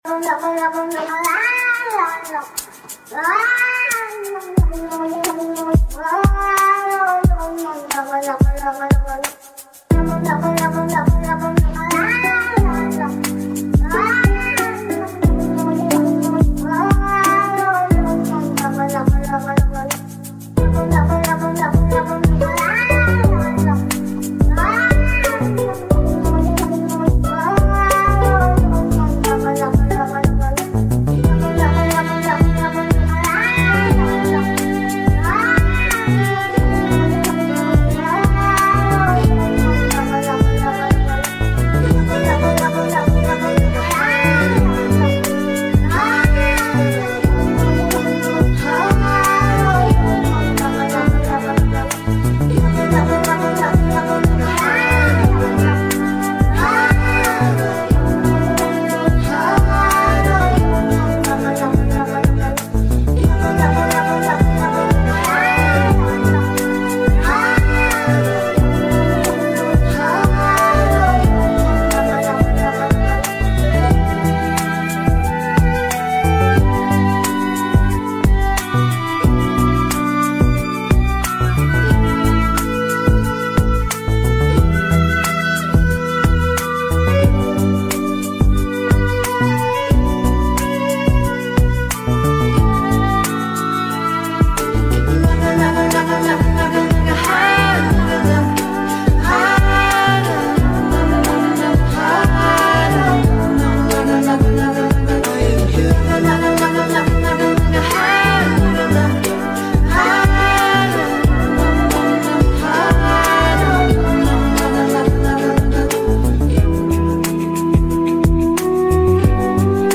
A singing cat actually made sound effects free download
brainwashing song